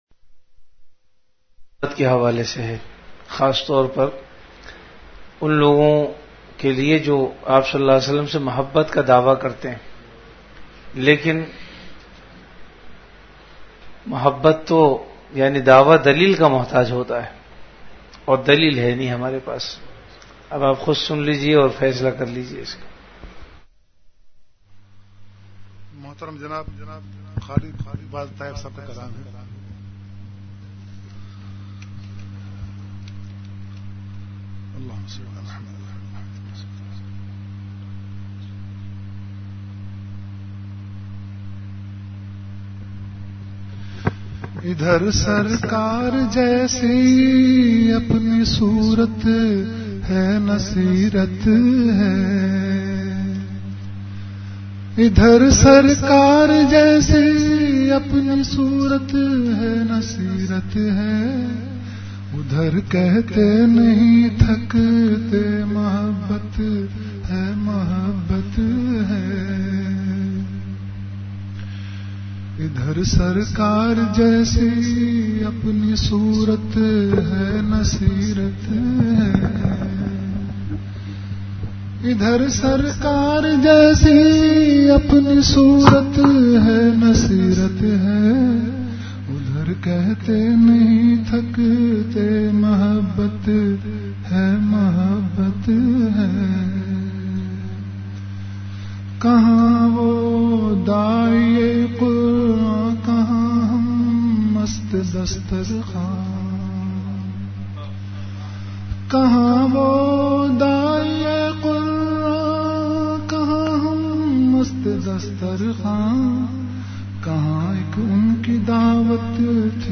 Delivered at Home.
Majlis-e-Zikr · Home Sahaba Ka Ishqe Rasool(SAW